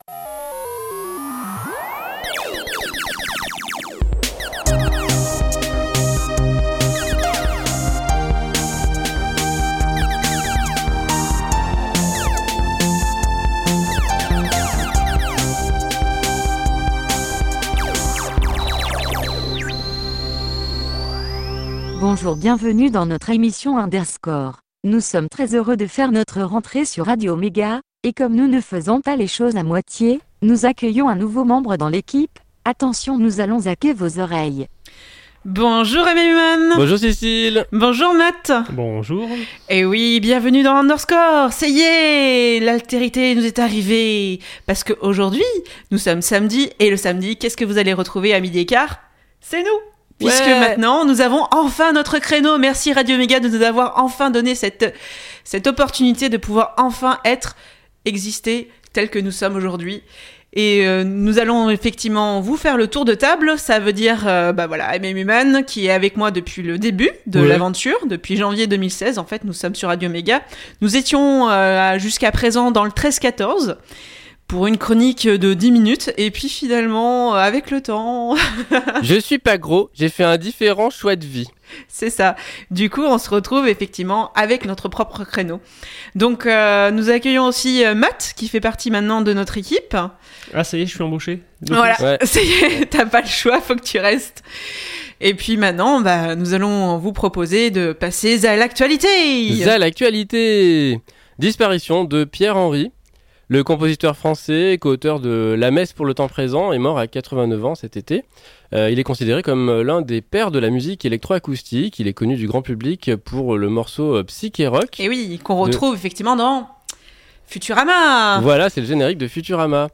Toujours sur Radio-Méga à Valence (99.2 FM), et en podcast ici.